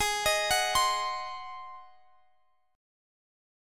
Ab7 Chord
Listen to Ab7 strummed